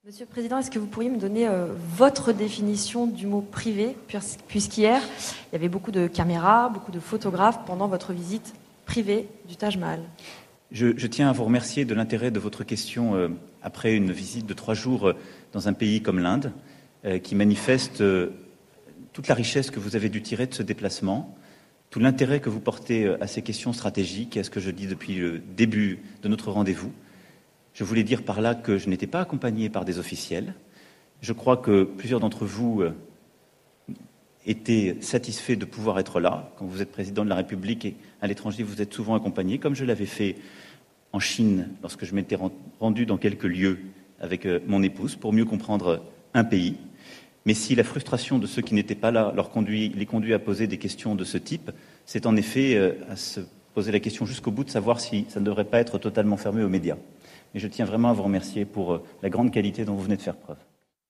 Après sa visite "privée" au Taj Mahal dimanche 11 mars 2018, Macron lors de la conférence de presse du lundi 12 mars s'est offusqué d'une question d'une journaliste sur le caractère "privé" de sa visite !
Réaction d’un gosse pris la main dans la bonbonnière, pris en faute, il bafouille et ironise sur le manque de professionnalisme de la journaliste, alors qu'il n’ose même pas balancer sa vacherie en la regardant droit dans les yeux, mais au contraire en détournant la tête avec dédain.